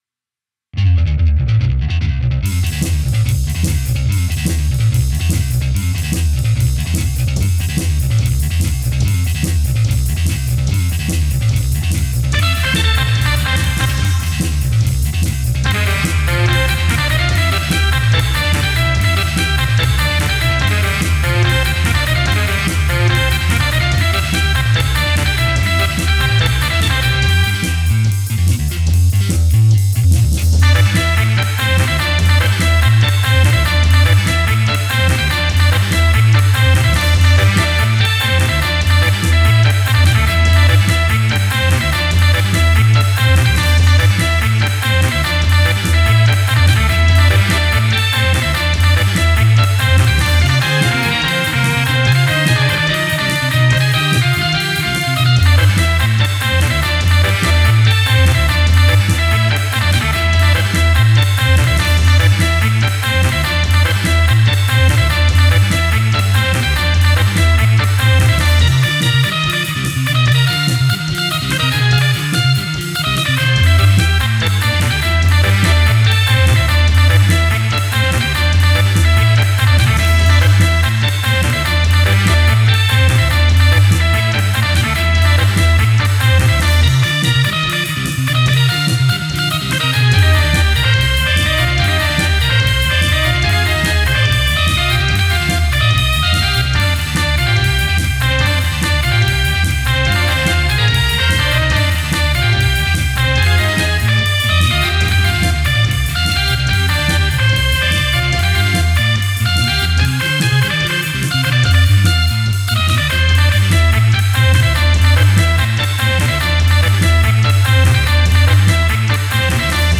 The following are direct recordings of VST instruments rendering a real-time MIDI output stream from µO: